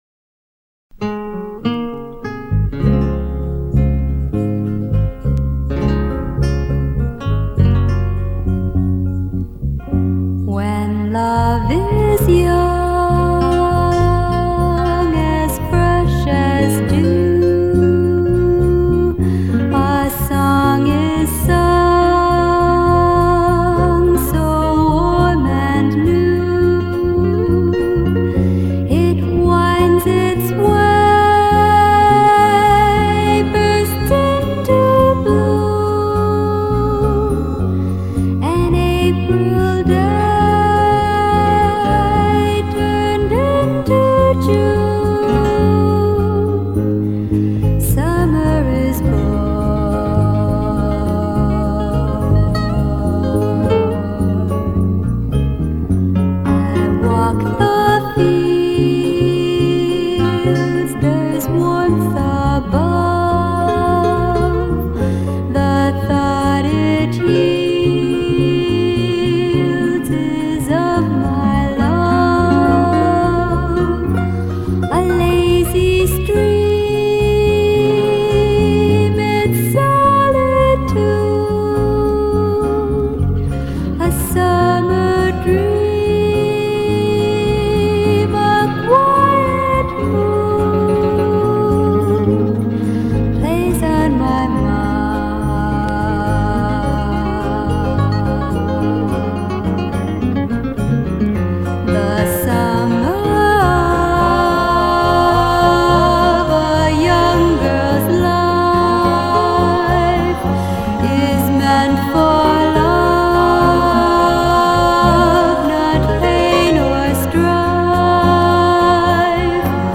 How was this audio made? this mixtape will soak up the sun and brighten your day!